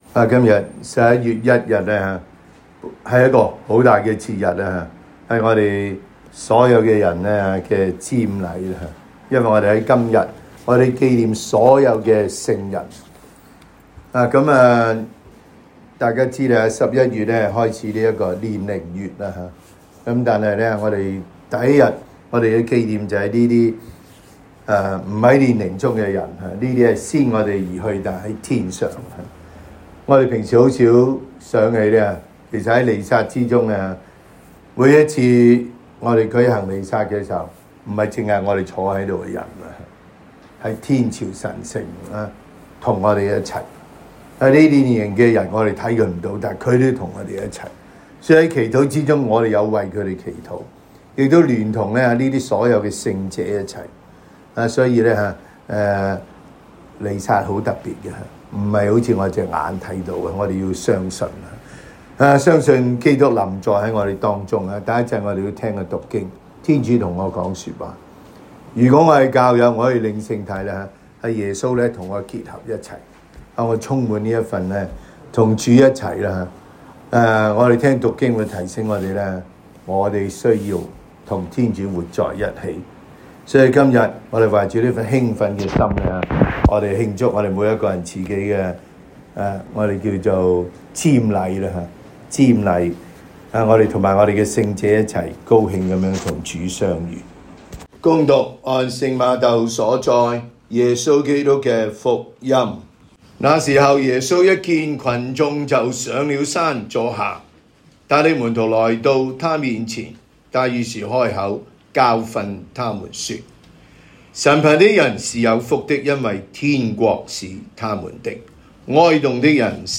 每日講道及靈修講座